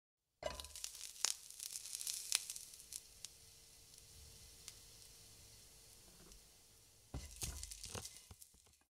grilling.ogg